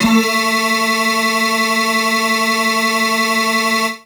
55bg-syn14-a3.wav